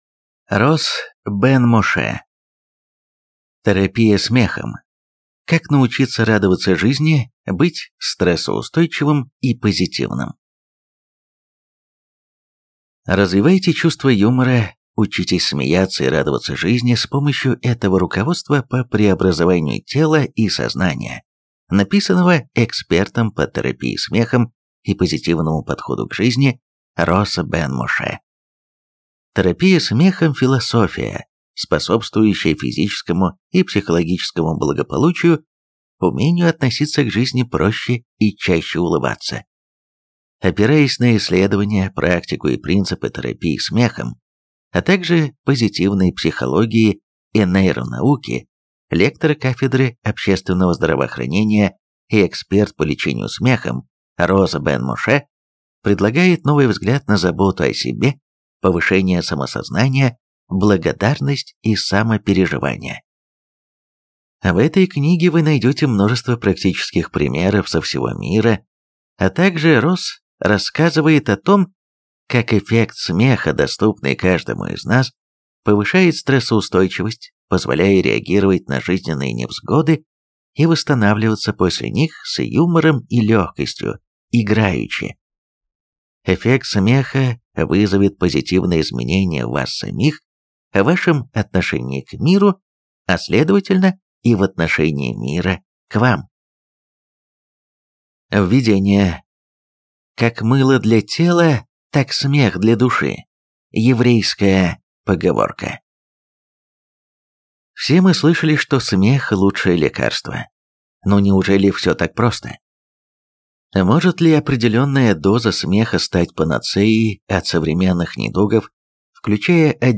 Аудиокнига Терапия смехом. Как научиться радоваться жизни, быть стрессоустойчивым и позитивным | Библиотека аудиокниг